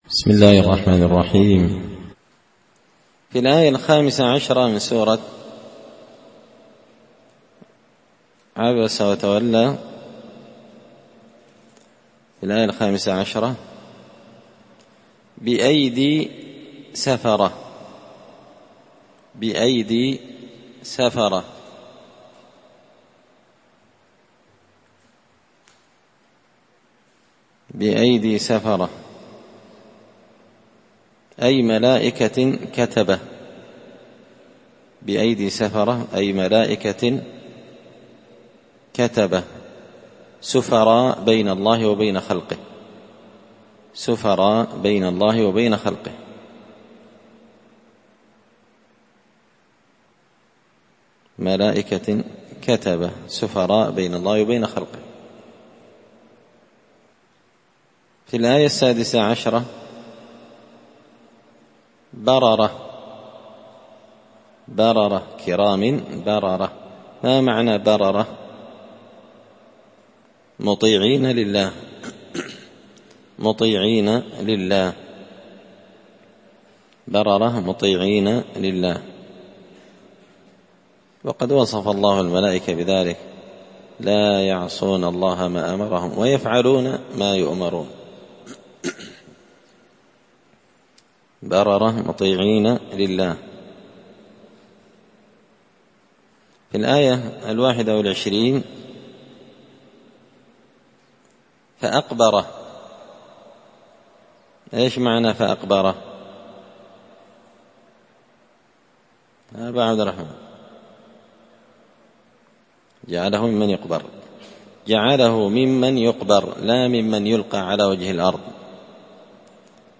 6الدرس-السادس-من-كتاب-زبدة-الأقوال-في-غريب-كلام-المتعال.mp3